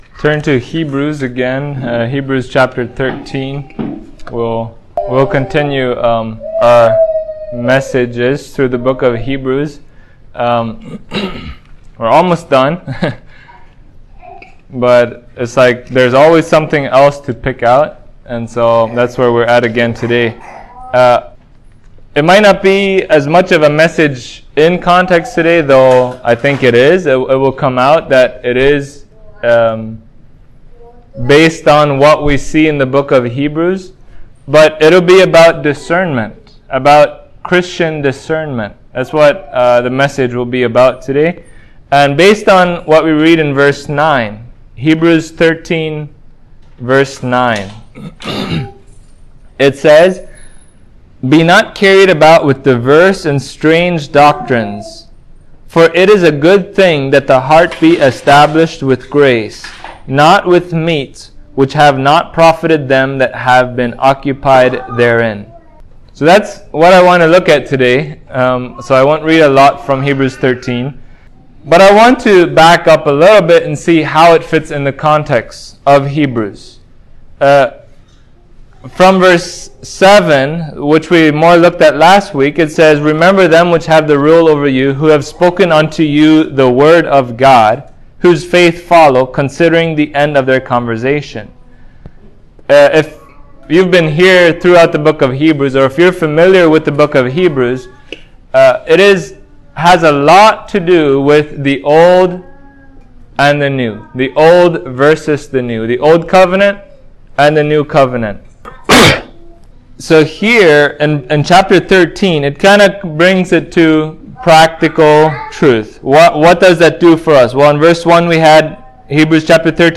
Hebrews 13:9 Service Type: Sunday Morning As Christians